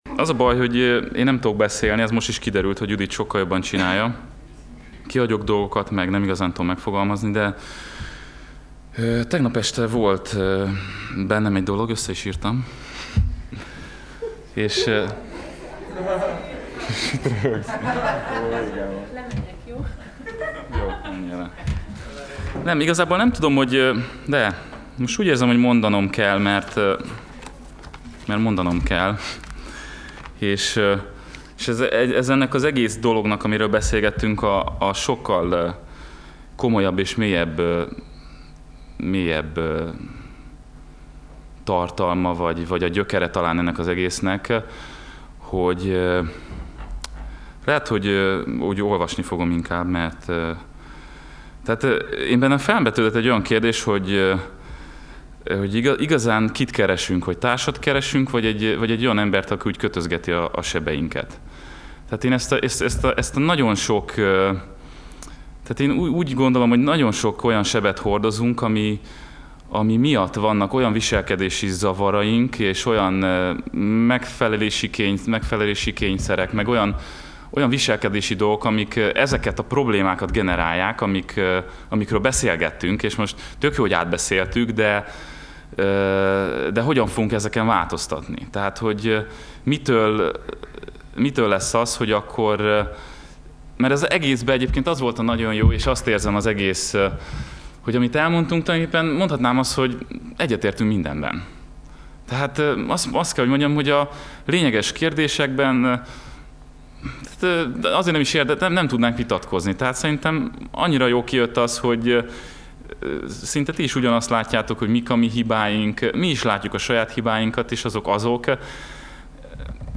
Sorozat: VE Konferencia 2010 Alkalom: Konferencia